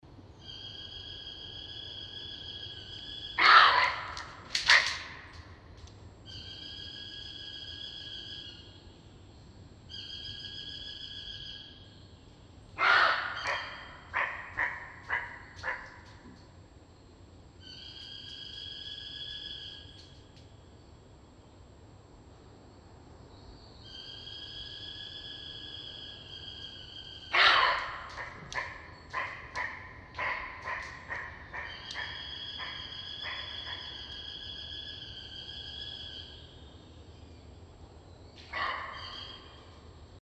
На этой странице собраны натуральные звуки косули — от нежного фырканья до тревожных криков.
Косуля кричит в лесу